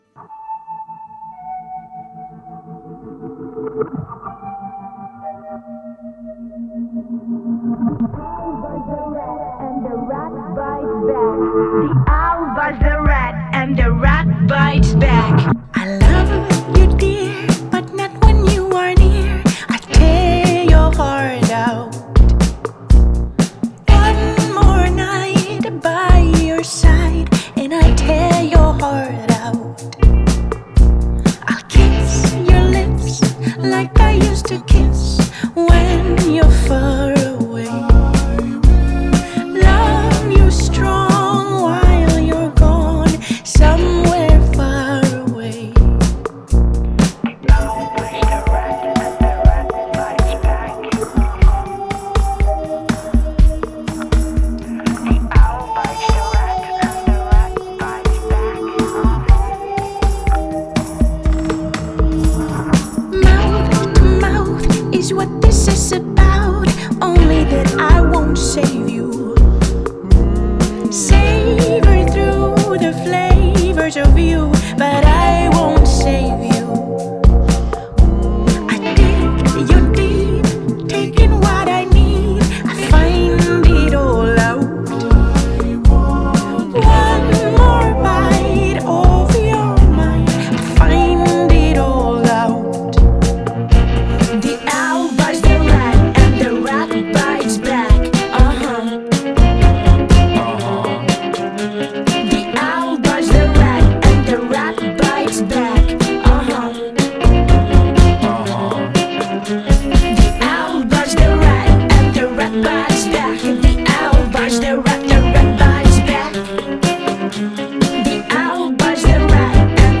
Genre: Indie Pop, Female Vocal, Experimental